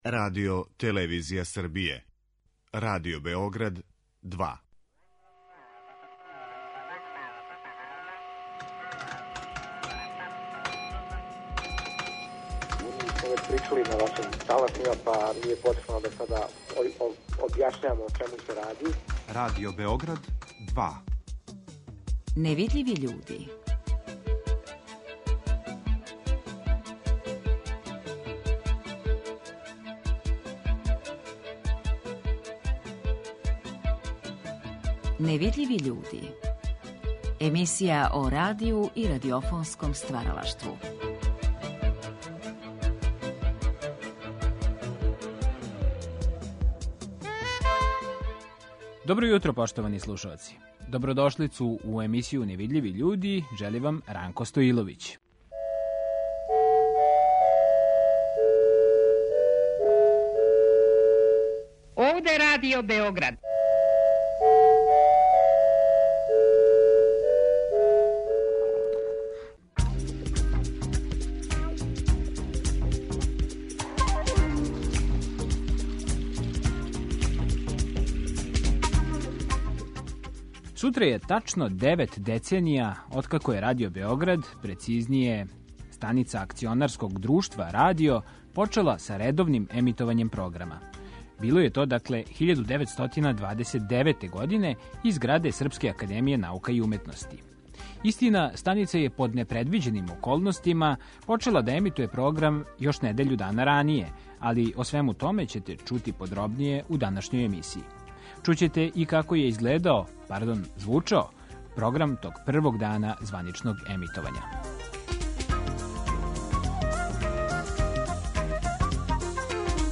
Чућемо како је звучао програм тог првог дана емитовања.